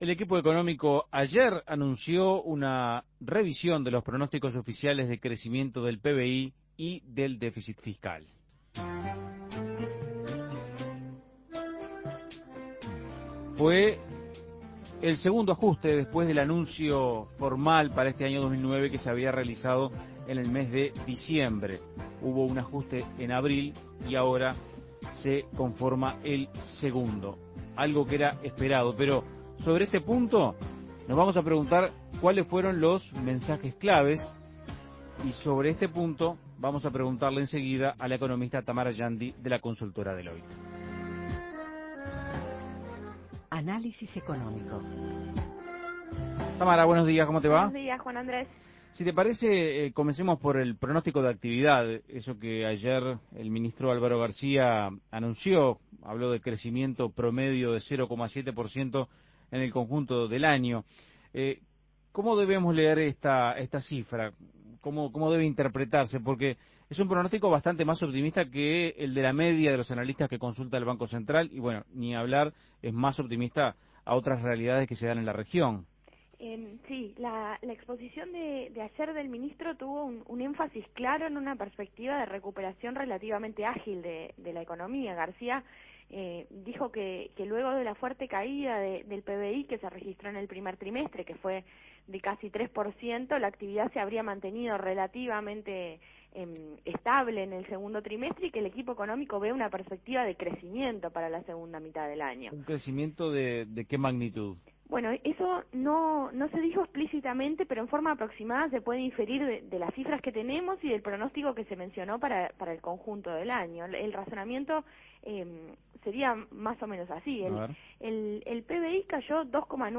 Análisis Económico ¿Cuáles son las claves de los nuevos pronósticos de crecimiento y déficit fiscal que anunció el gobierno?